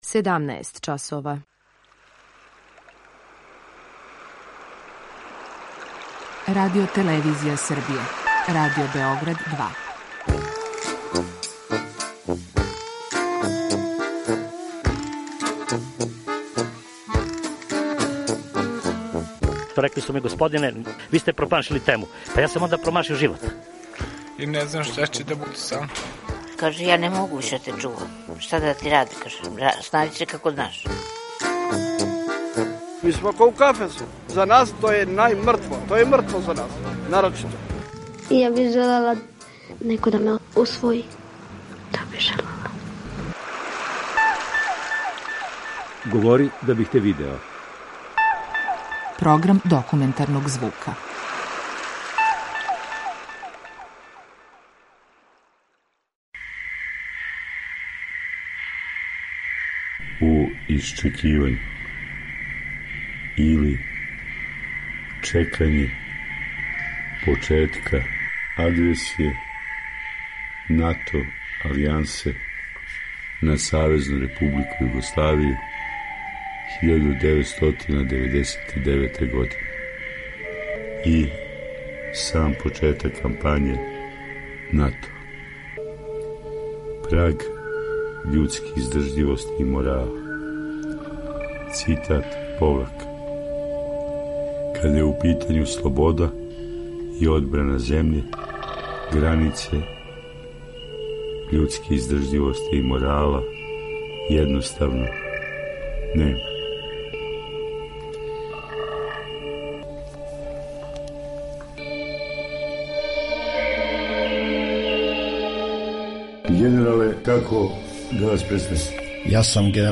Серија полусатних документарних репортажа